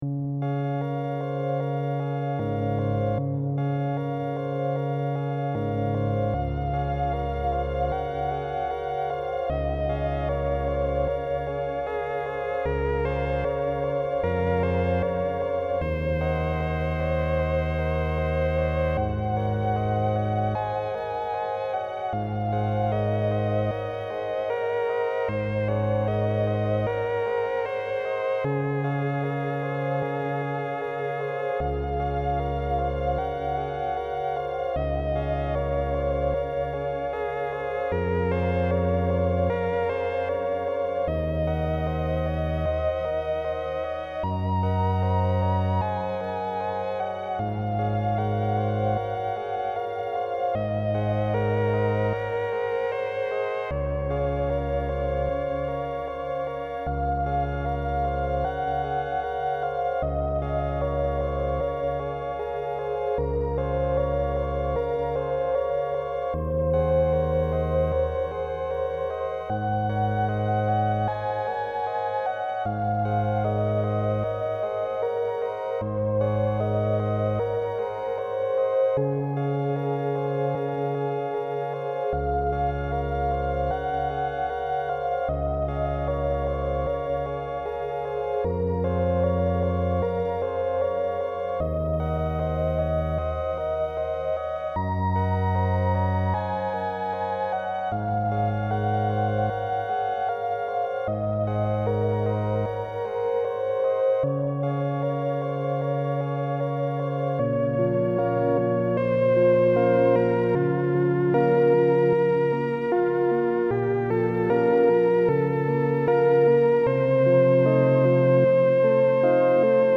The samples were finally trimmed to one-second loops and all were painstakingly edited to remove DC-offsets and cut to zero-crossings to ensure seamless looping.
A MIDI performance of Edwin H. Lemare’s Andantino in D-Flat on the Robb Wave Organ based on Truette’s notes. Artificial reverb has been applied.